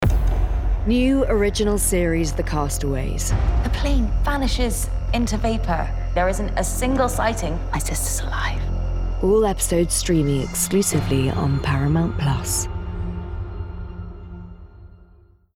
20/30's Neutral/London,
Assured/Engaging/Gravitas